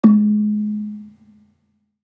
Gamelan Sound Bank
Gambang-G#2-f.wav